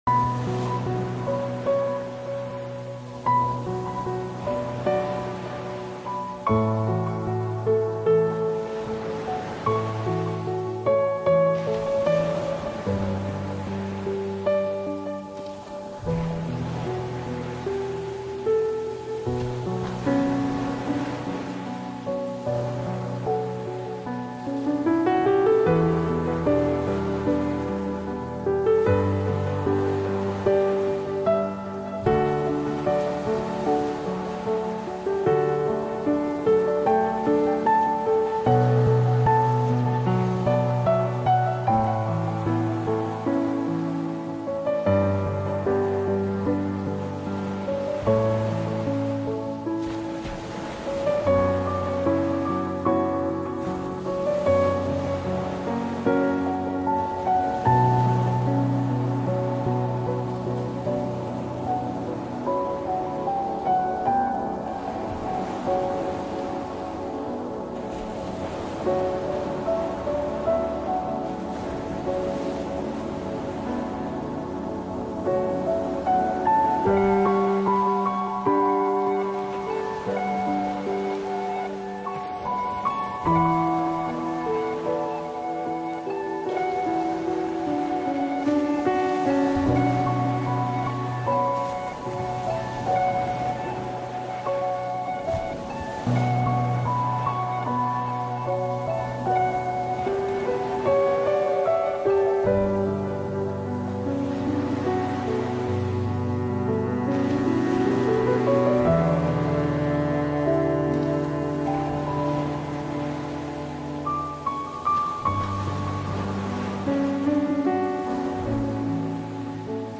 La fréquence 83 Hz nettoie les poumons
La-frequence-83-Hz-nettoyer-les-poumons.mp3